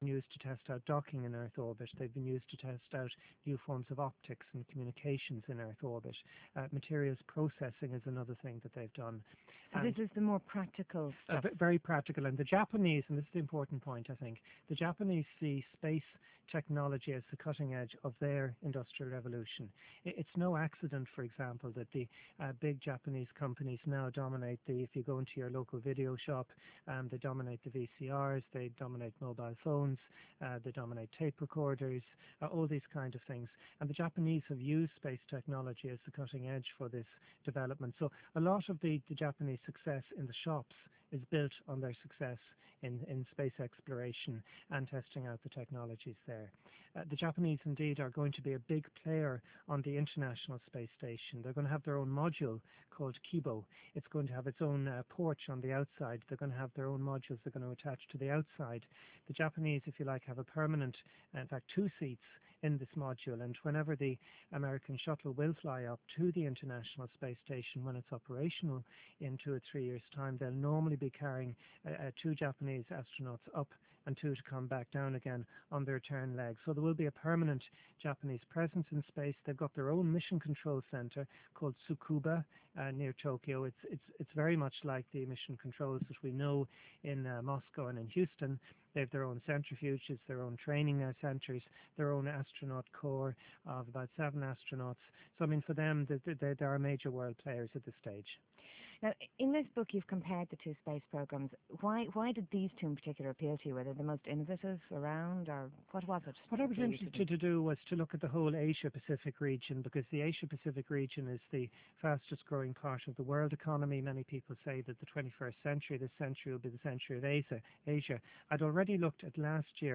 For those who are interested, there was this article in the Irish Times, and this radio interview (our section begins 3:05 into the recording) with R.T.E. We've also been featured, of course, on the NASA Space Settlement design page.